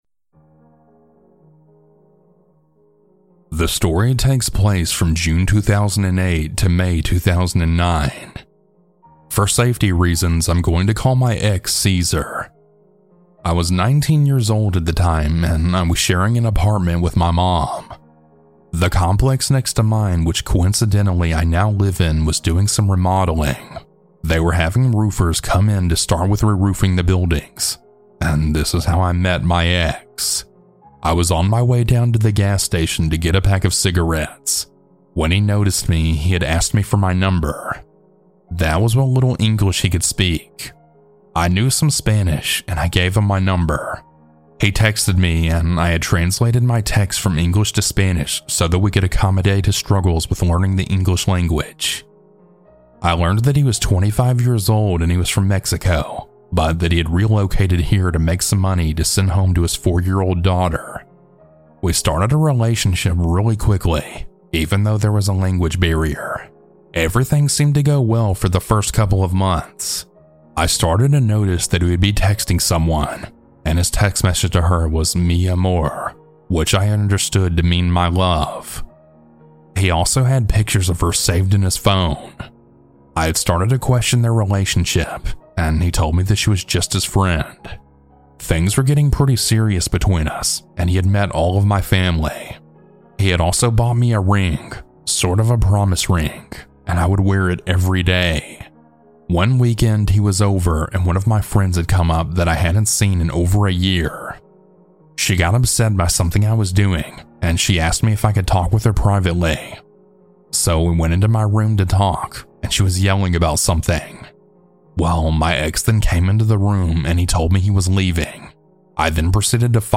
- mmmhmmm Huge Thanks to these talented folks for their creepy music!